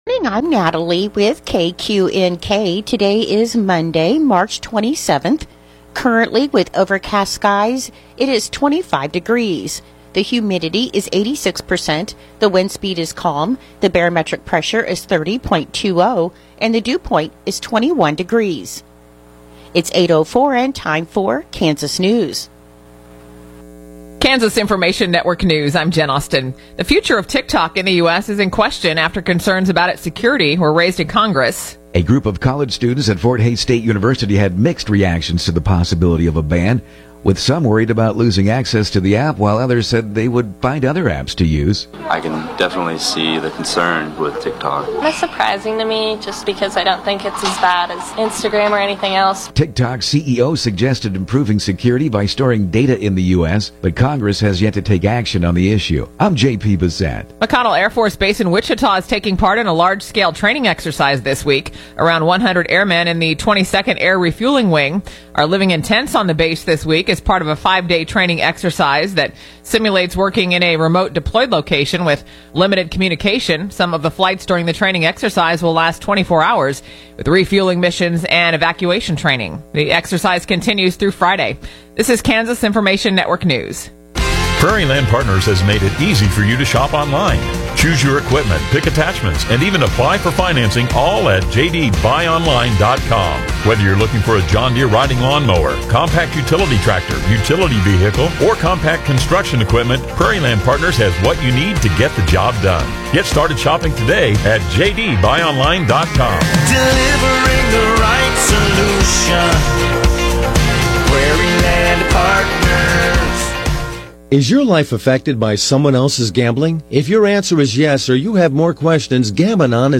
The KQNK Morning News podcast gives you local, regional, and state news as well as relevant information for your farm or home as well. Broadcasts are archived daily as originally broadcast on Classic Hits 106.7 KQNK-FM.